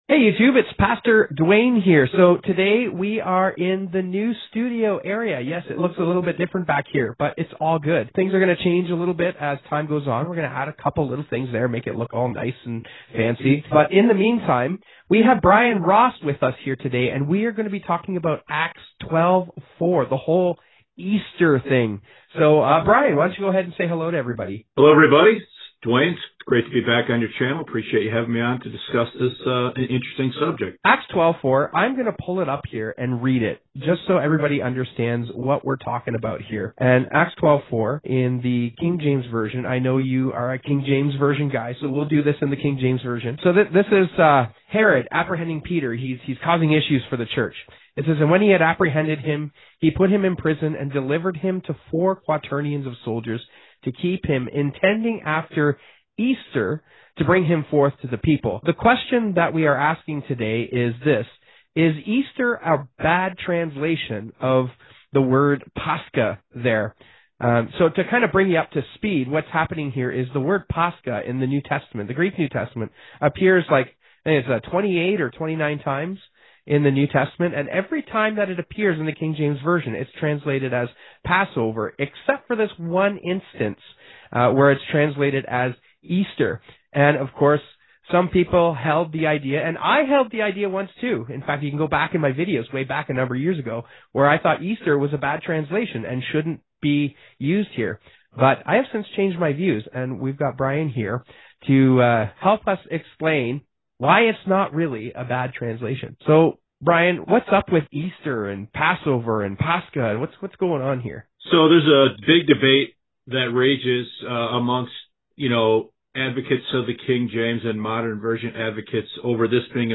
Guest Appearances & Interviews